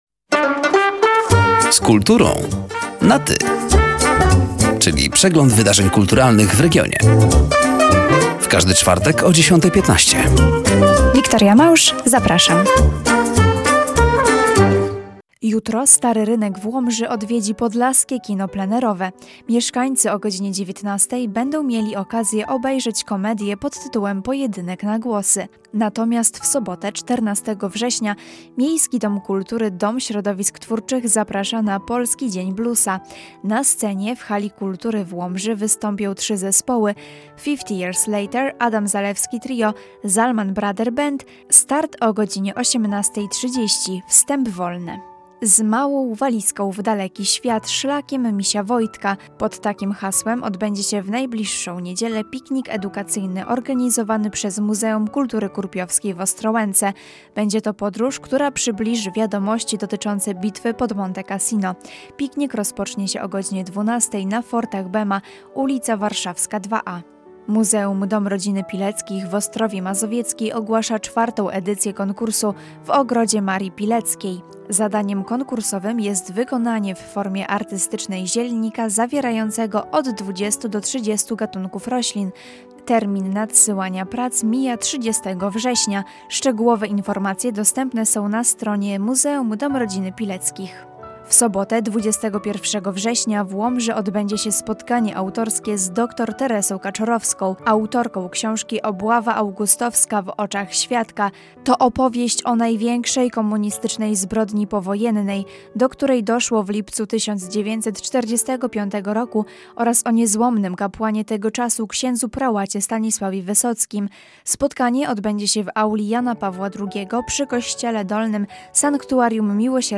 Zapraszamy do odsłuchania rozmowy oraz zapoznania się ze zbliżającymi wydarzeniami kulturalnymi: